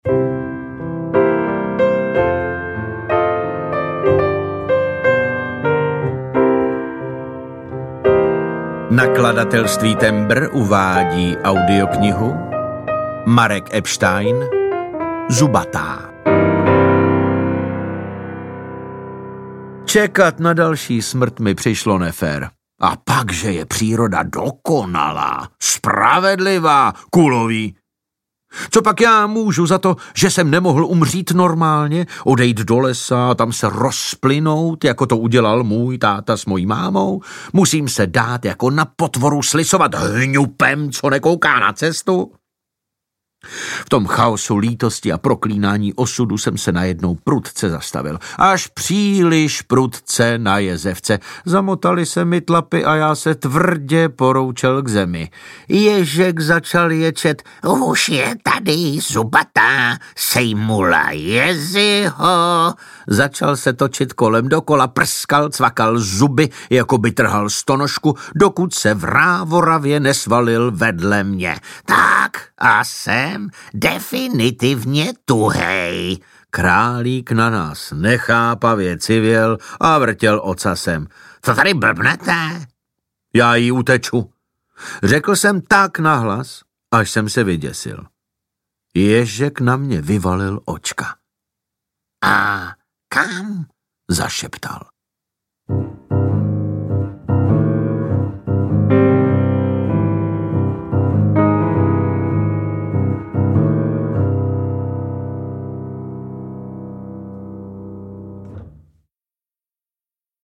Zubatá audiokniha
Ukázka z knihy
• InterpretDavid Novotný